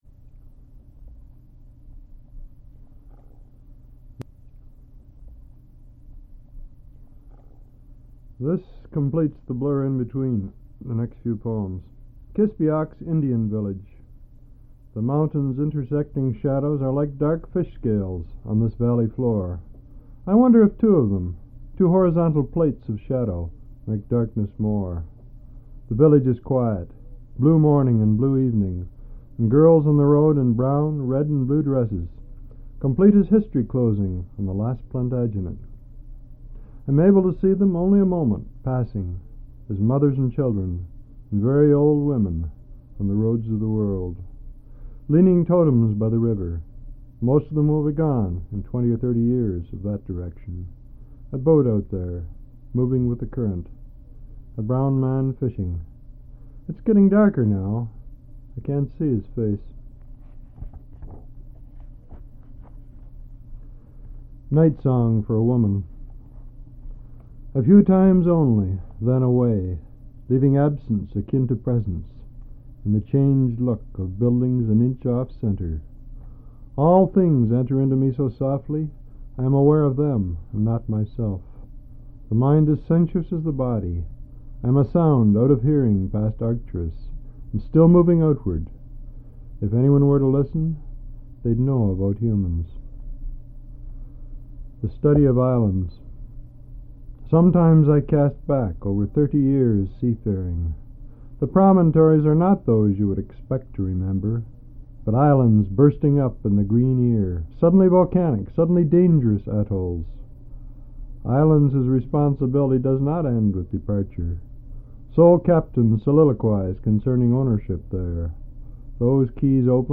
Al Purdy reads his poetry;